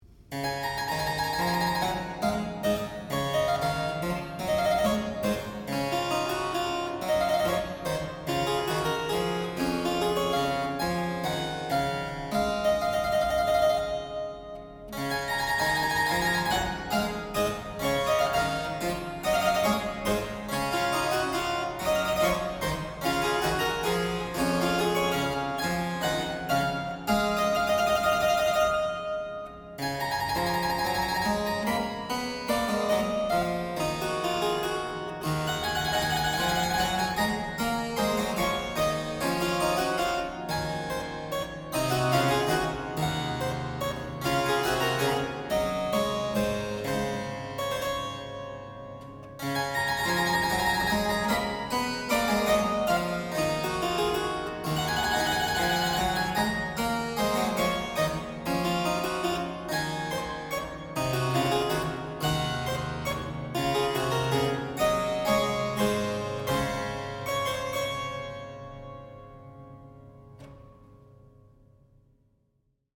mp3Seixas, Carlos de, Sonata No. 20 in D major, mvt.
Minuet